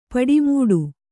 ♪ paḍi mūḍu